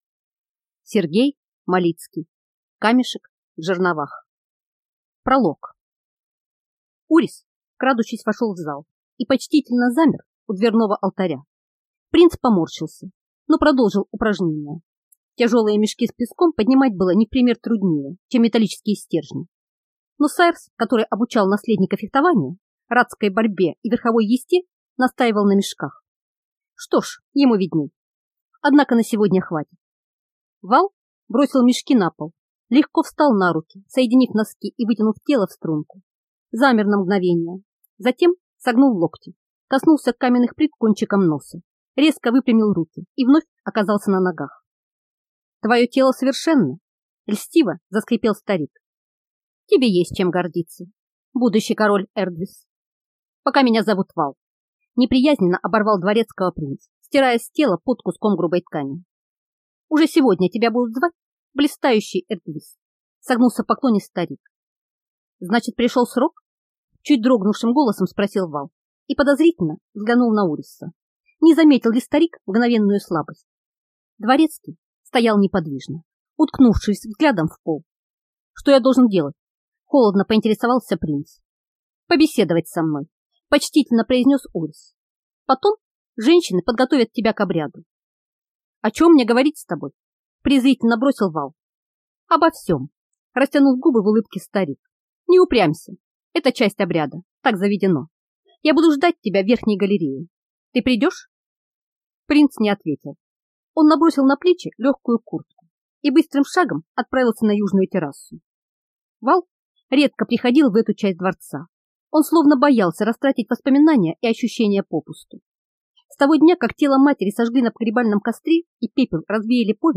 Аудиокнига Камешек в жерновах | Библиотека аудиокниг